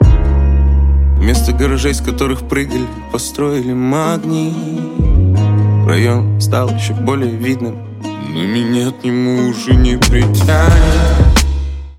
Хип-хоп
Жанр: Хип-хоп / Русский рэп